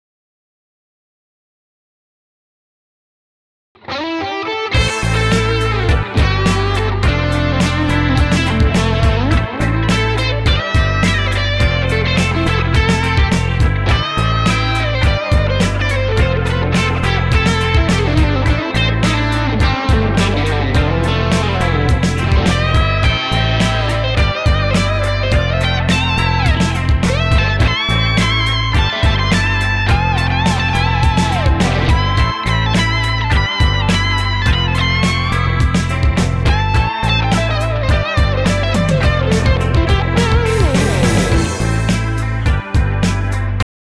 使用環境ですが完全にライン撮りです。
ギターはテレキャスターのリア【シングルコイル】です！
最後にオケ中でアドリブソロを弾いてみました！
一発撮りですのであんま良くないかな？笑（予防線）
KEYはA mなのでAmペンタ中心にロックに弾いてみました^のでOS2のサウンドを聴いてみて下さい！！_